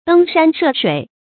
登山涉水 注音： ㄉㄥ ㄕㄢ ㄕㄜˋ ㄕㄨㄟˇ 讀音讀法： 意思解釋： 爬山過水。比喻歷盡艱難。